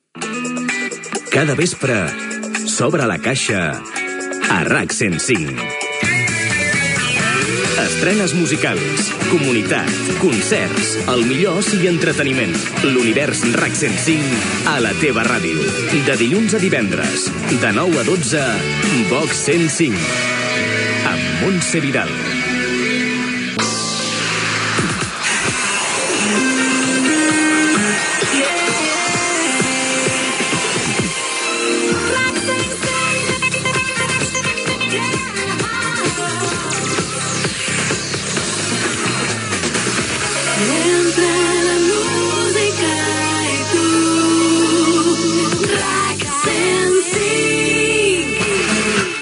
Indicatiu cantat de l'emissora.